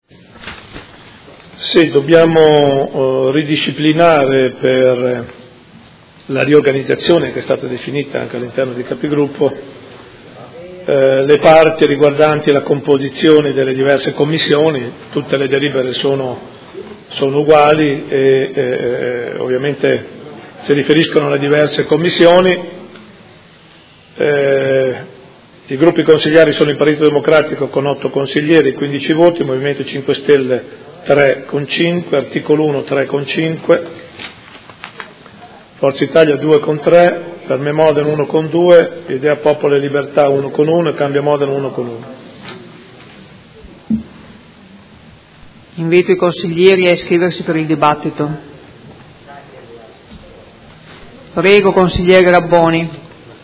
Sindaco